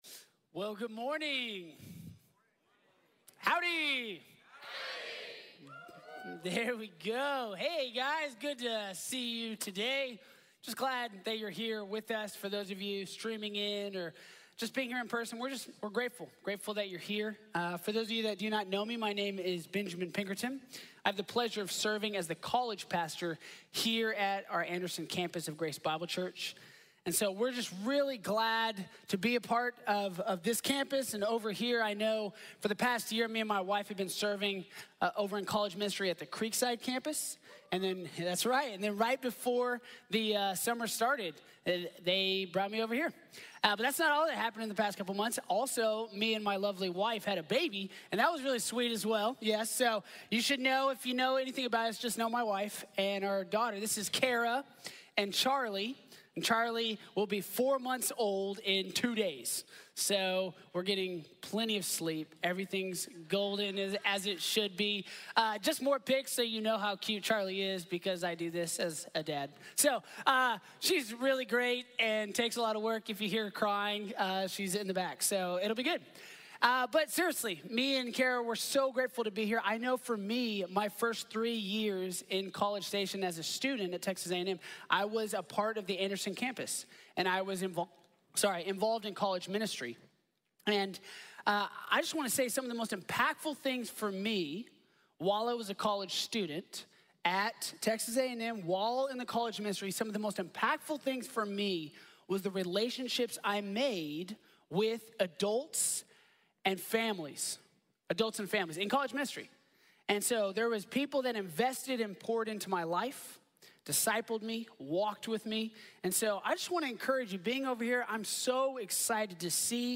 Adam & Eve | Sermon | Grace Bible Church